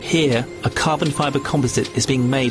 The hɪjə pronunciation of here can be heard from Prof Mark Miodownik saying here a carbon fibre composite is being made: